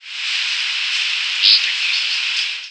Buzz calls
Rough-winged Swallow
The calls in the table are arranged roughly from the finest buzz (Lincolns and Swamp Sparrows) to the coarsest buzz (Dickcissel and Northern Rough-winged Swallow).
These are generally the longest calls with the coarsest buzz. With these species, consider especially the pitch (highest in Indigo and lowest in Dickcissel and Northern Rough-winged Swallow) and slope of the call.